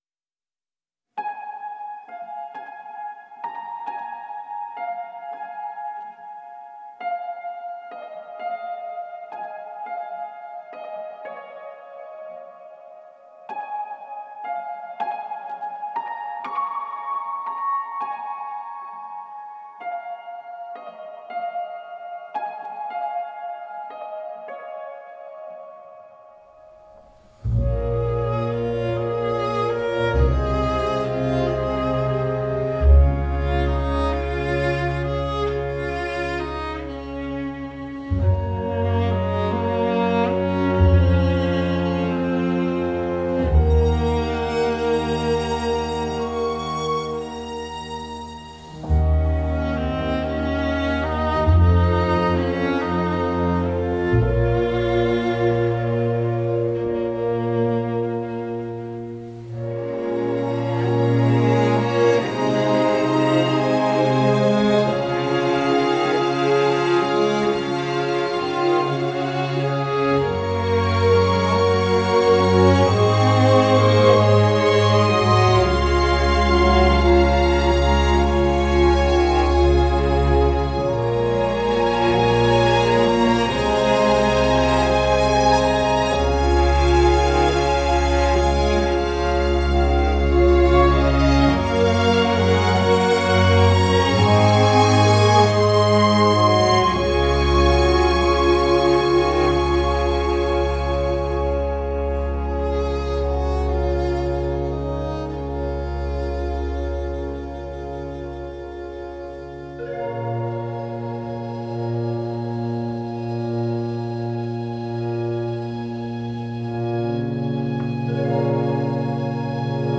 موسیقی بیکلام موسیقی متن فیلم